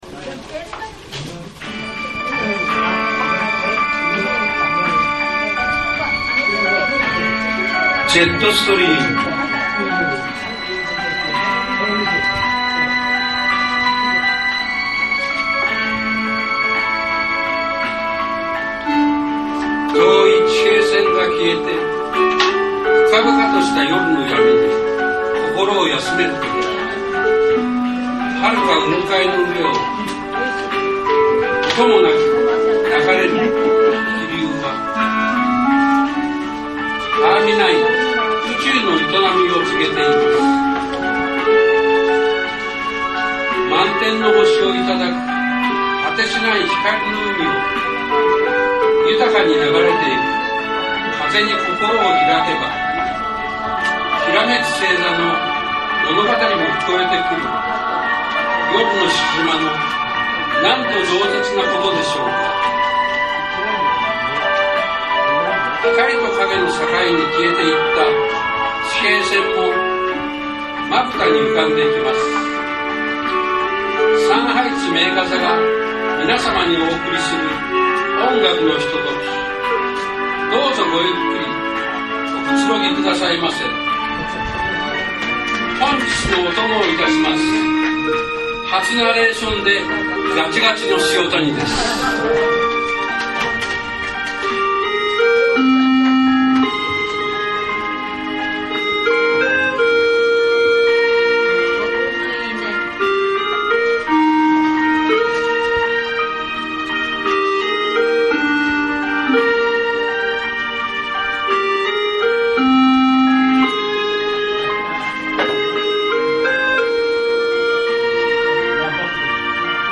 240615_会場風景
映画とコンサートと懇親会 [青春の思い出に花が咲きます]